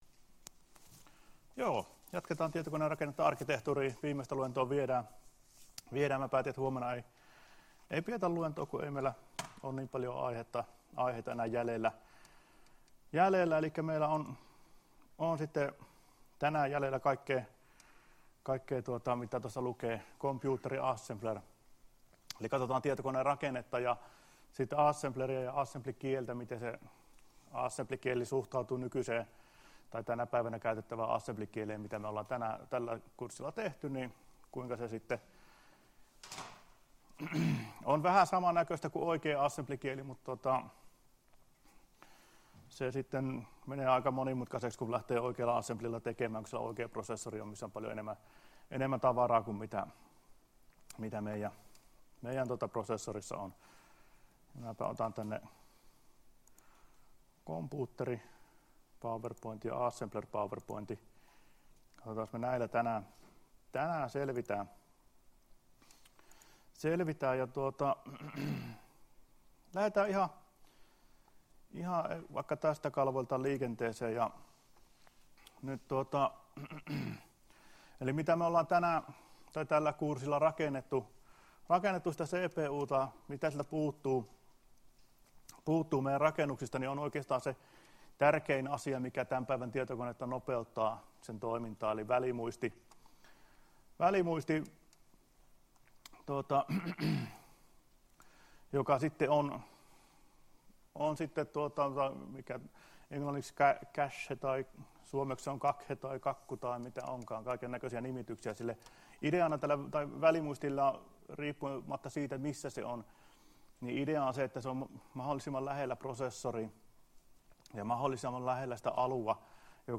Luento 18.10.2016 — Moniviestin